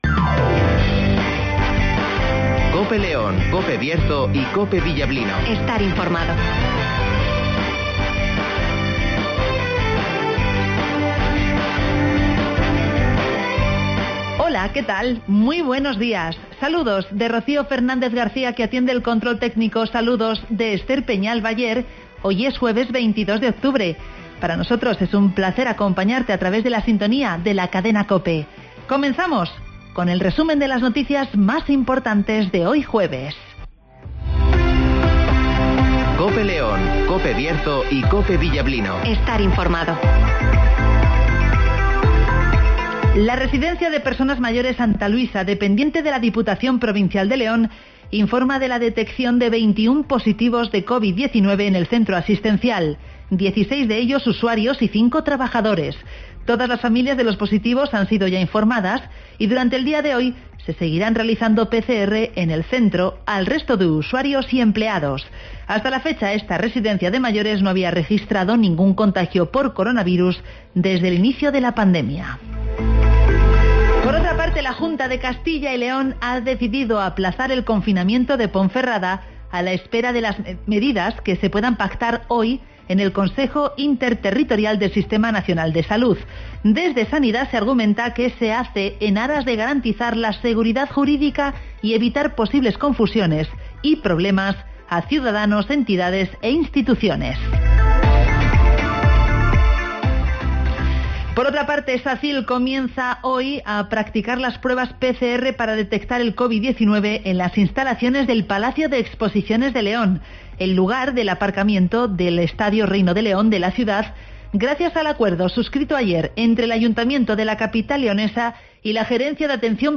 -Avance informativo